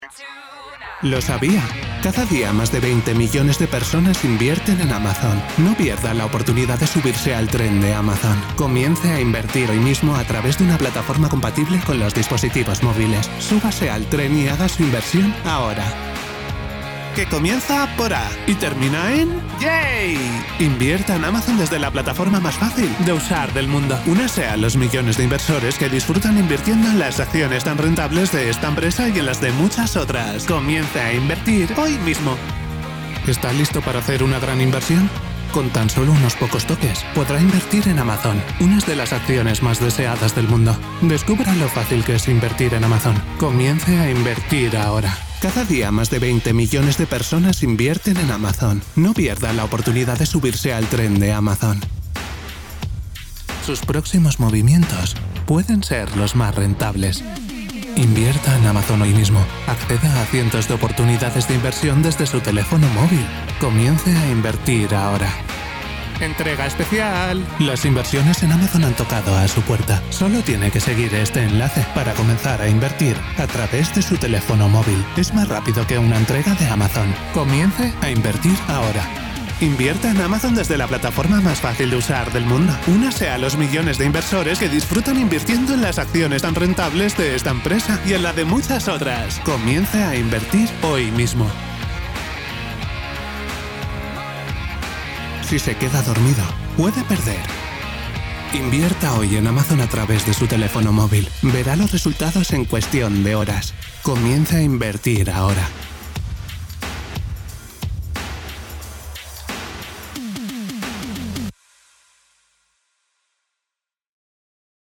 Relaxed Spanish Voice Over Talent
Happy Investing Spanish Demo Reel
Valenciano
Middle Aged